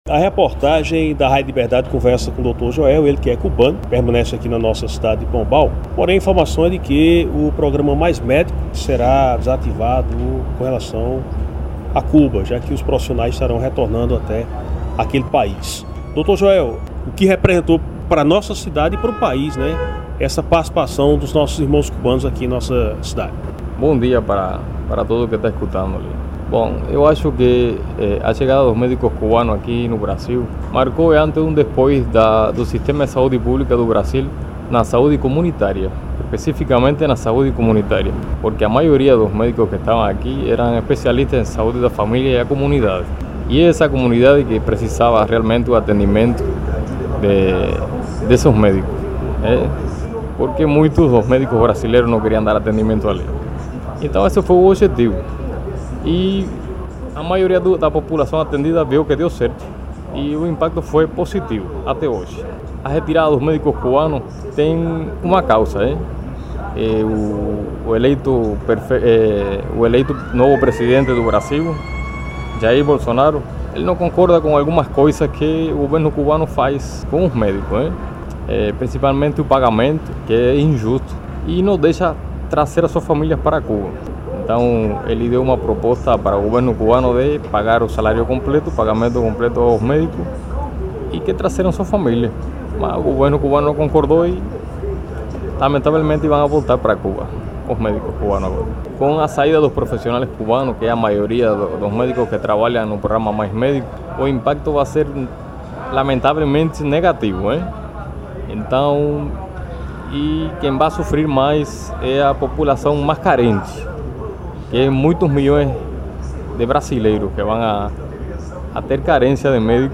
MÉDICO CUBANO